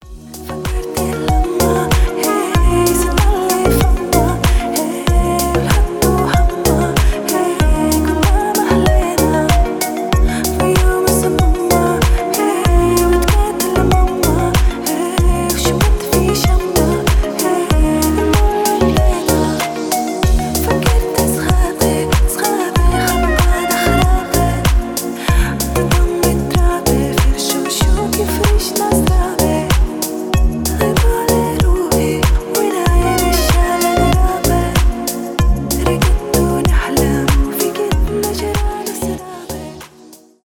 поп
deep house
танцевальные